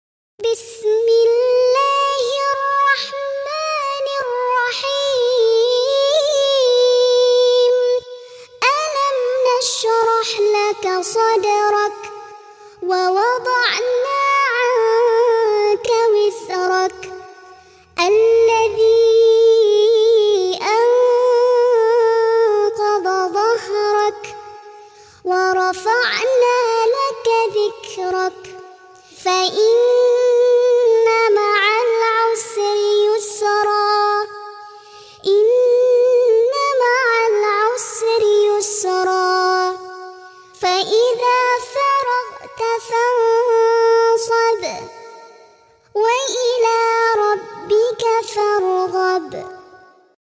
Ngaji Murottal Juz Amma